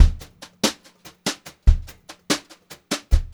144GVBEAT2-R.wav